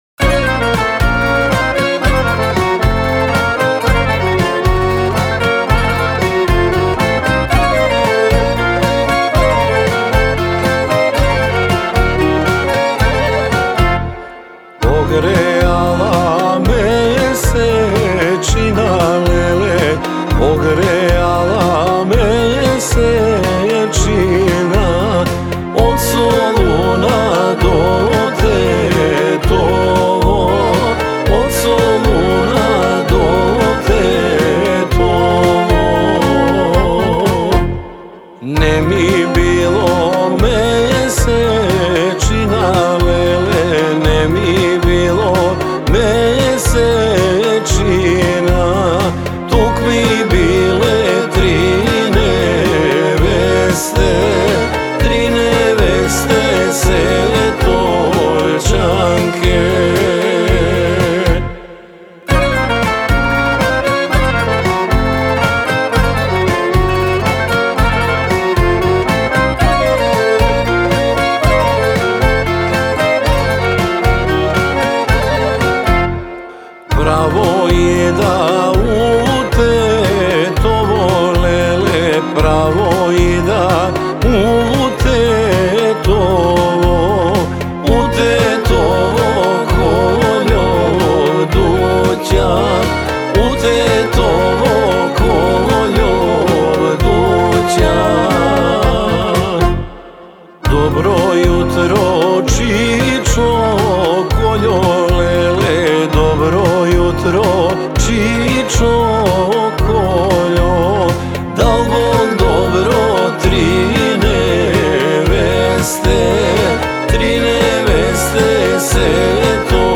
народна македонска песна